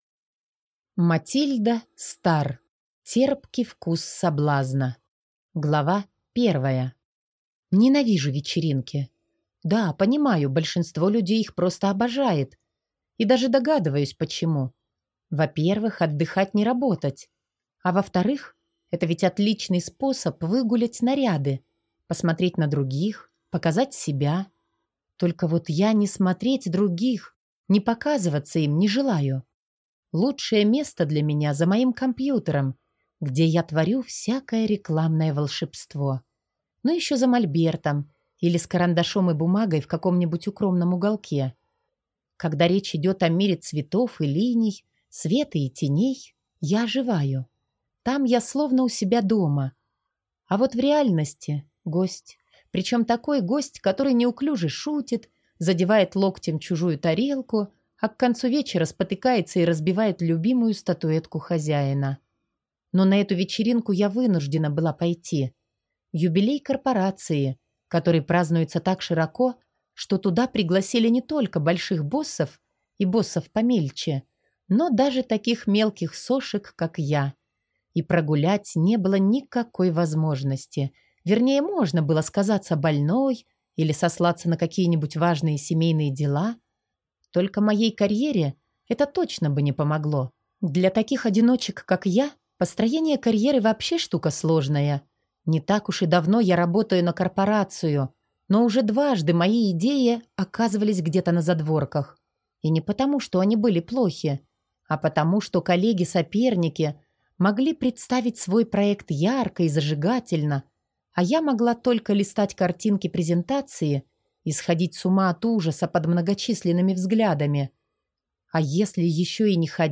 Аудиокнига Терпкий вкус соблазна | Библиотека аудиокниг